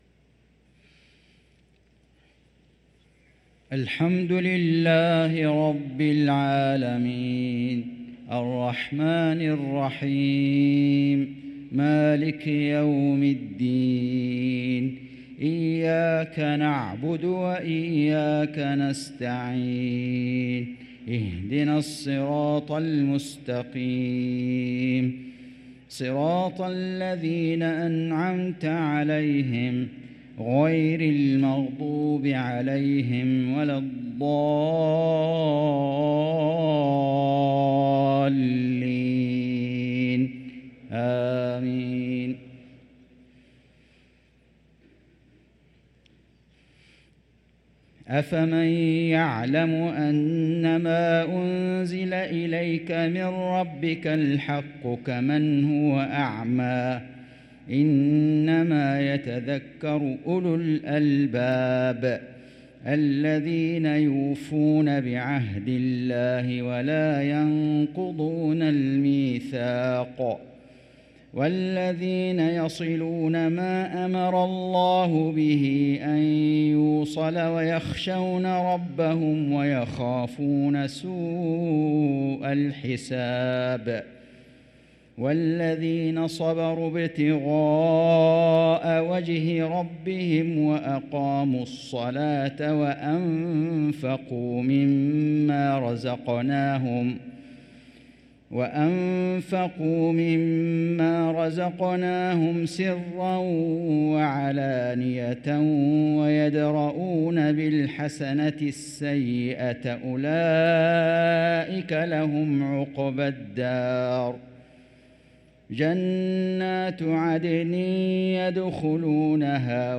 صلاة العشاء للقارئ فيصل غزاوي 2 صفر 1445 هـ
تِلَاوَات الْحَرَمَيْن .